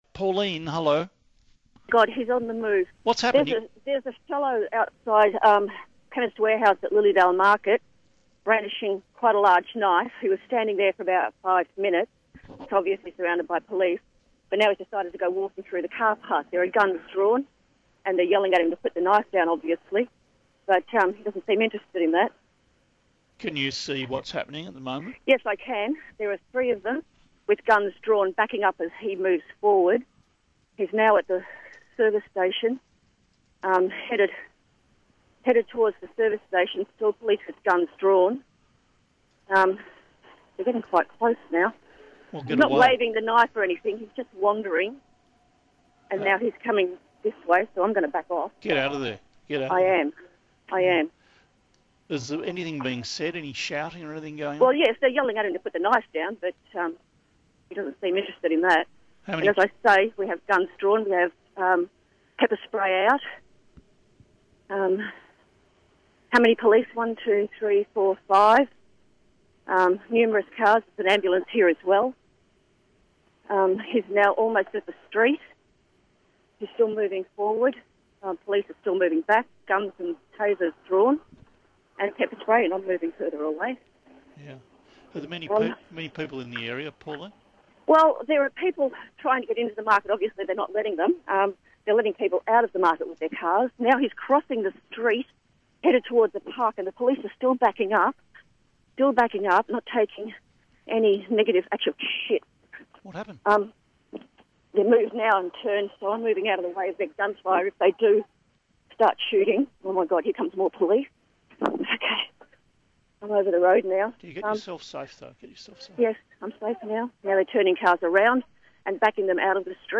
Police shots heard live on radio after confrontation with knife-wielding man
A man carrying a knife has been shot by police in Melbourne’s east, with the terrifying incident heard live on radio.
The talkback caller did not see the shooting, but was describing the confrontation when the shots were heard.
Eyewitness-narrates-moments-before-police-shooting-at-Lilydale-Marketplace.mp3